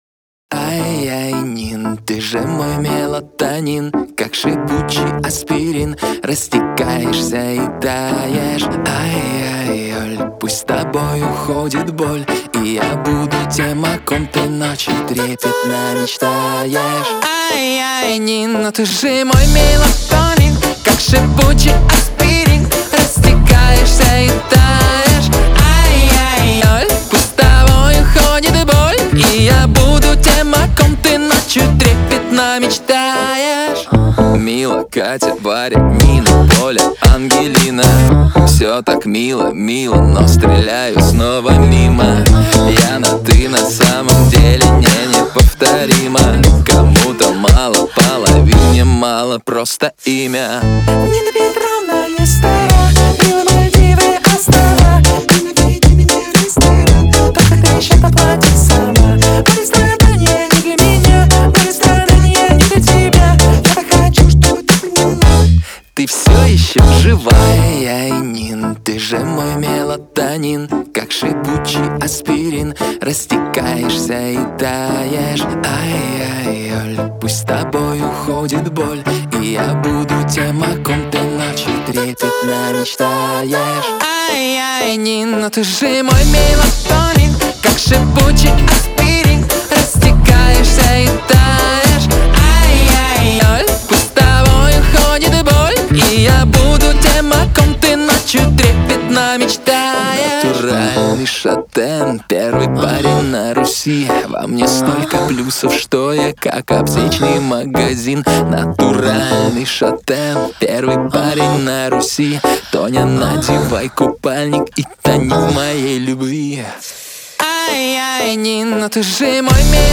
Поп музыка, Классическая